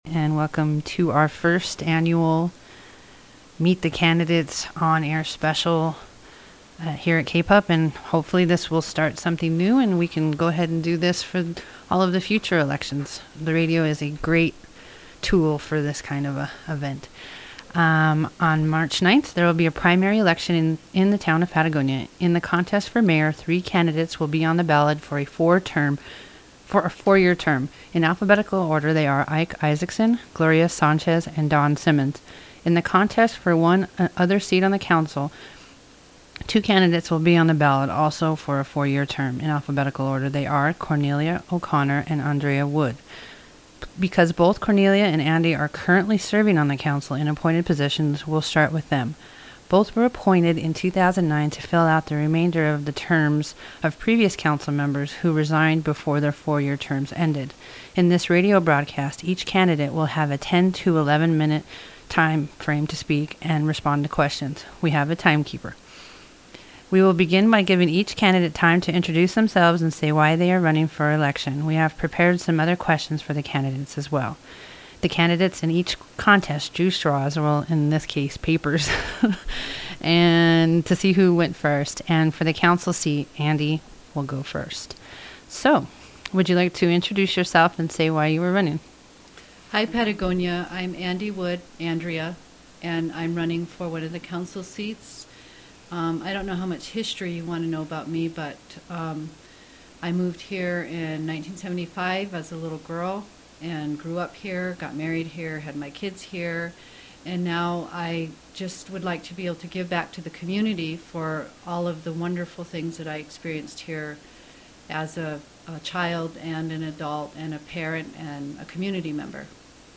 Candidate Interviews for Patagonia Town Council, aired February 28, 2010